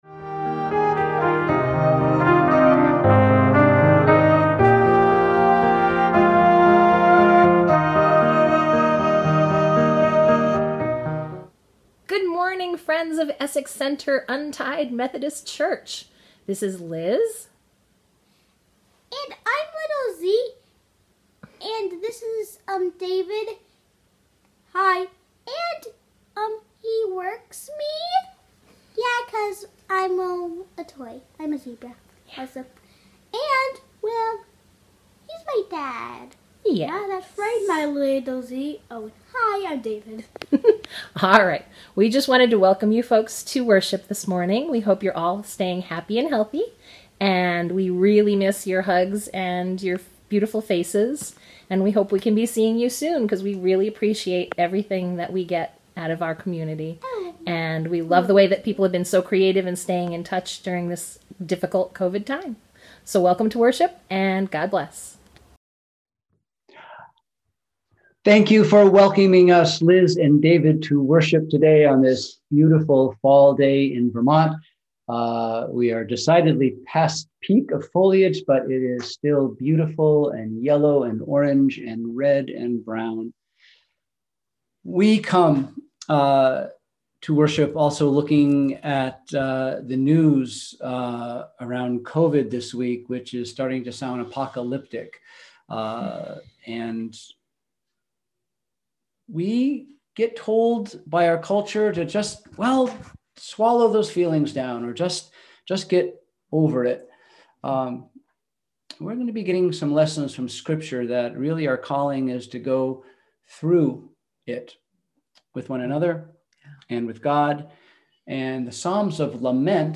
We held virtual worship on Sunday, October 18, 2020 at 10am.